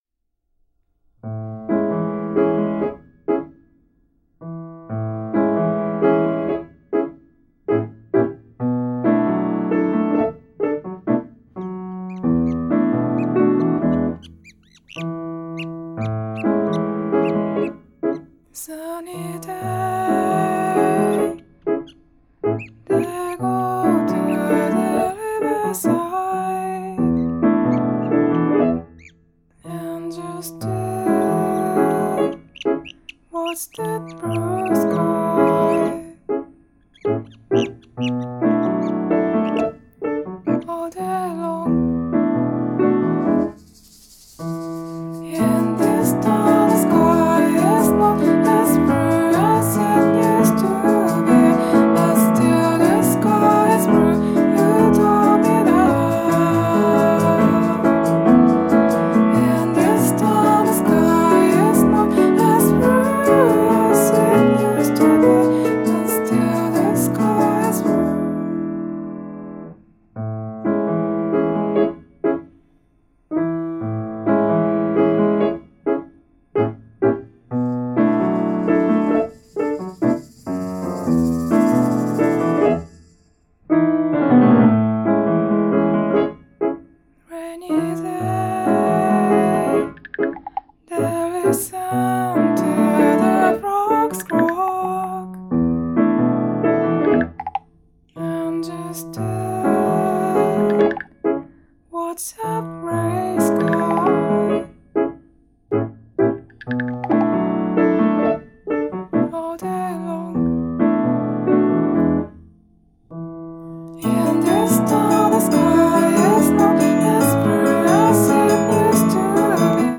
シンプルかつ端正なピアノと凛々しさと浮遊感が同居した歌が、淡く切ない詩情を呼び込む傑作！